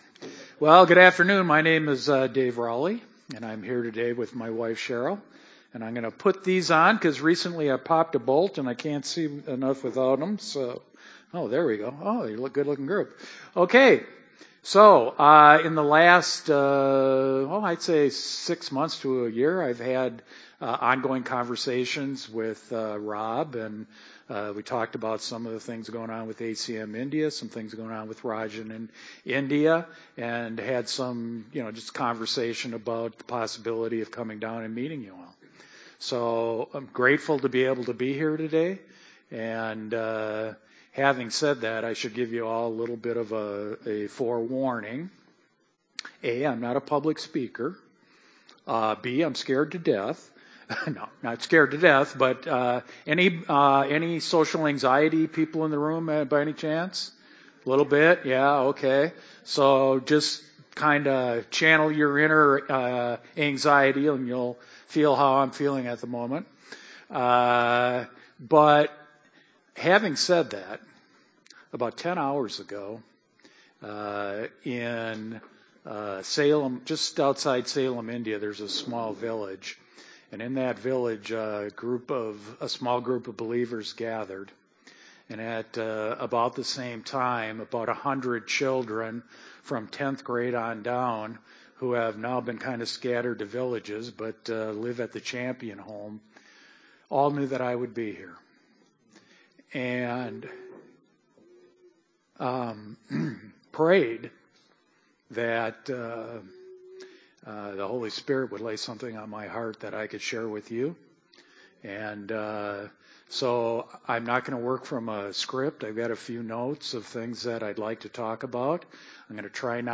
Passage: Luke 15:1-10 Service Type: Sunday Service